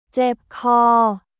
เจ็บคอ　ジェップ・コー